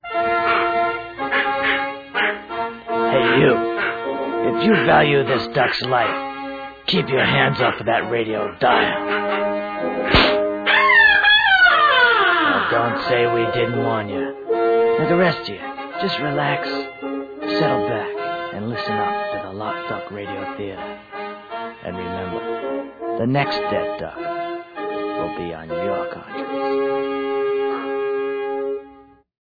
In the meantime, here are some lo-fi versions of the radio programs... these were done in 1978.